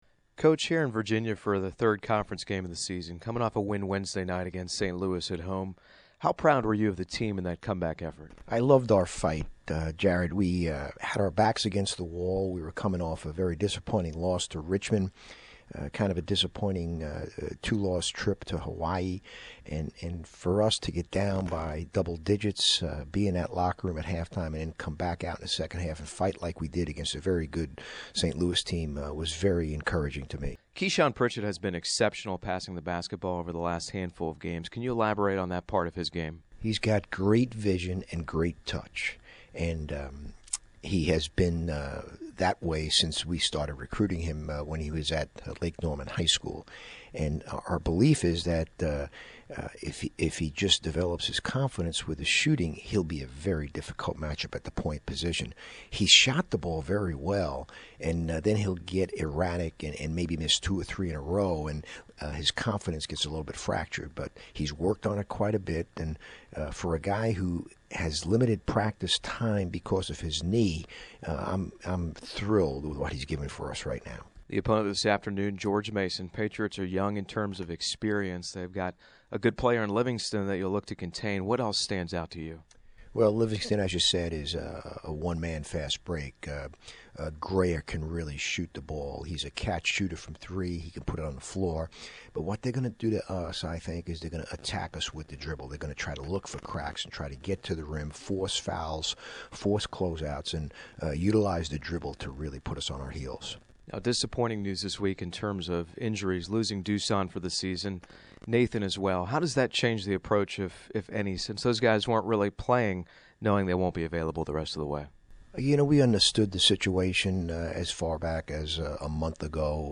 Pregame Interview